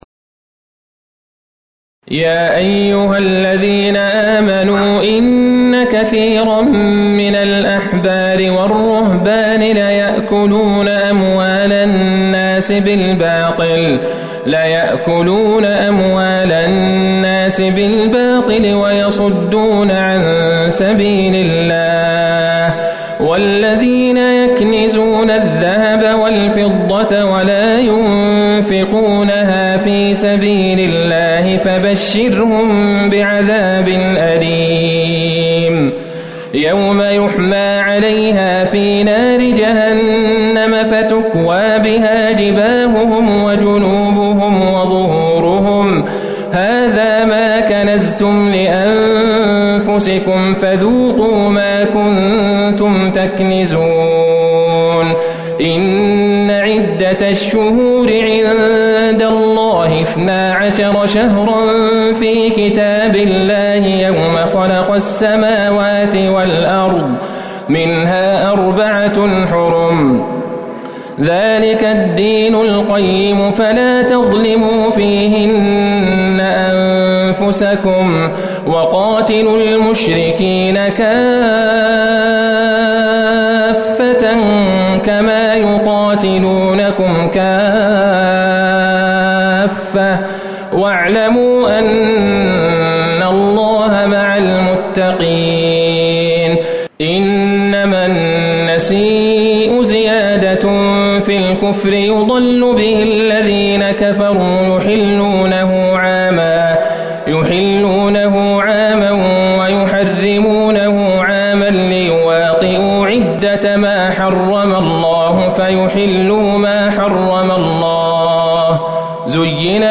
صلاة العشاء ١٤٤١/١/٨ من سورة التوبة | Isha prayer from Surah At-Tawba > 1441 🕌 > الفروض - تلاوات الحرمين